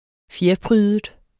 Udtale [ -ˌpʁyːðəd ]